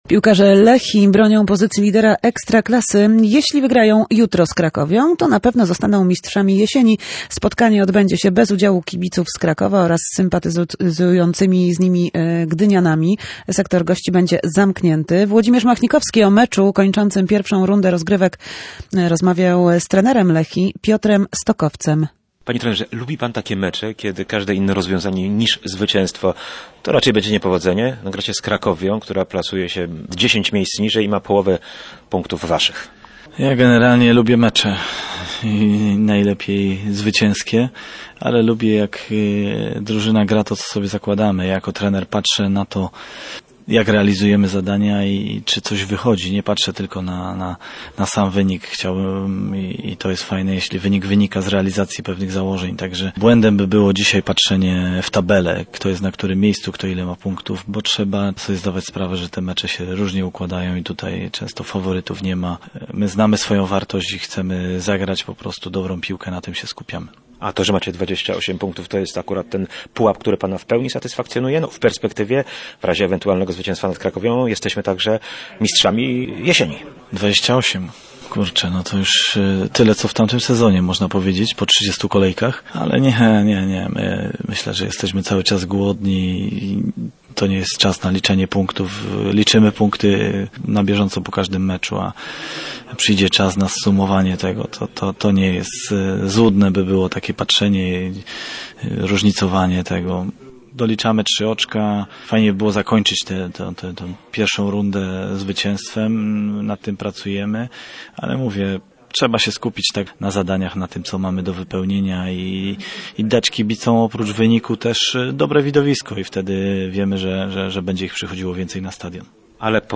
rozmawiał z trenerem Lechii Piotrem Stokowcem.